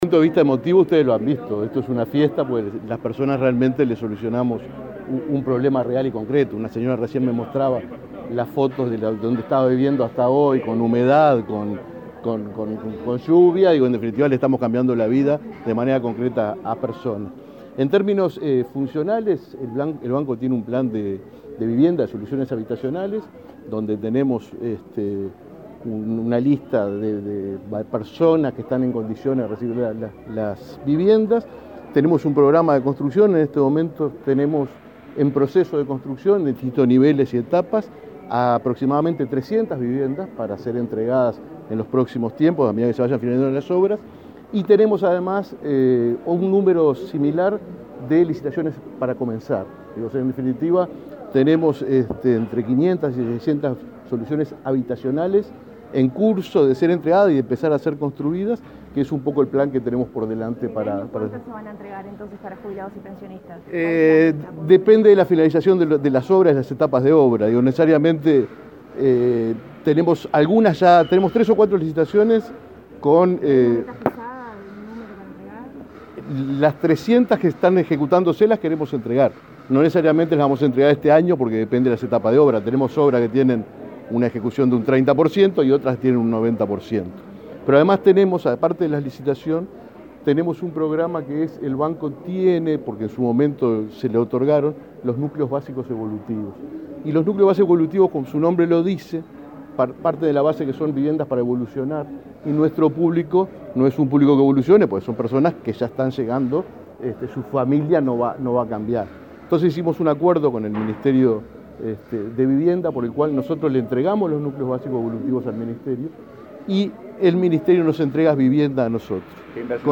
Declaraciones del presidente del BPS a la prensa
Luego, dialogó con la prensa.